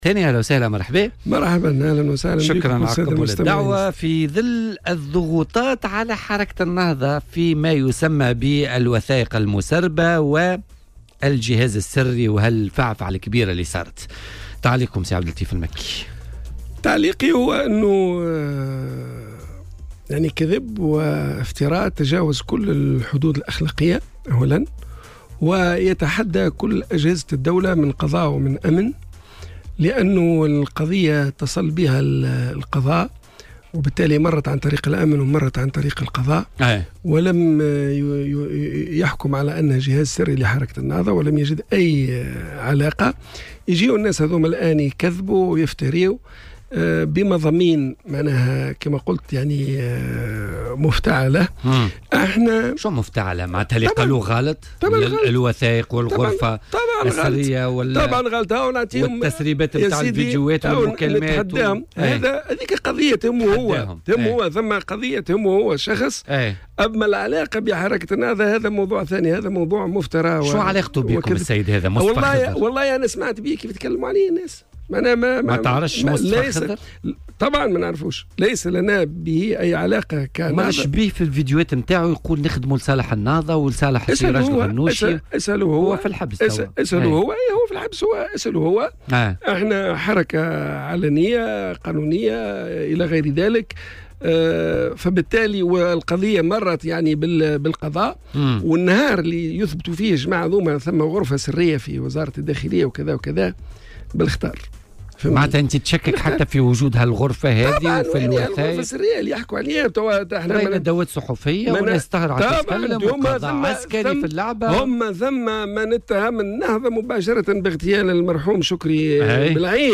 وأضاف ضيف "بوليتيكا" على "الجوهرة أف أم"، أنه يتحدى كل من يثبت وجود غرفة سوداء "غرفة سرية" بوزارة الداخلية، معتبرا هذه الادعاءات من باب الاستثمار السياسي، وفق تعبيره في تعليقه على الاتهامات التي وجهتها هيئة الدفاع عن الشهيدين شكري بلعيد ومحمد البراهمي إلى النهضة حول تورطها في جريمة الاغتيال والمس من الأمن القومي من خلال جهاز سري لديها.